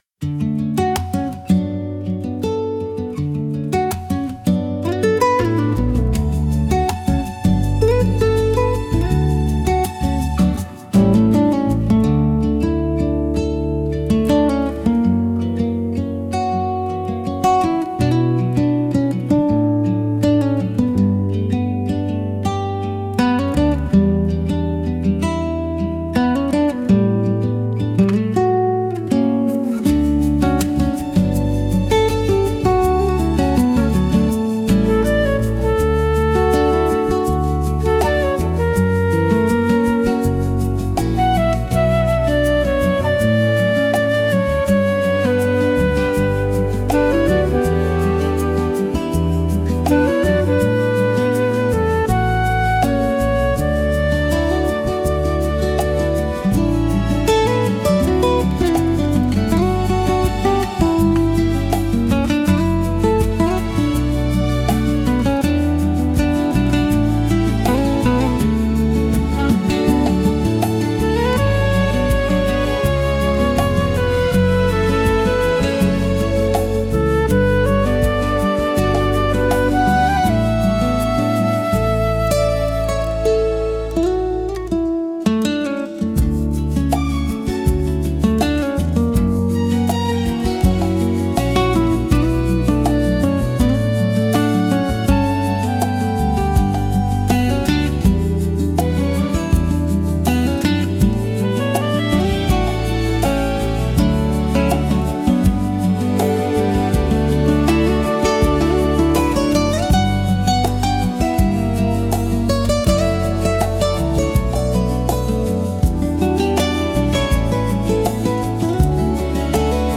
聴く人に安心感と癒しを与え、静かな集中や心の安らぎをサポートしながら、邪魔にならない背景音楽として活用されます。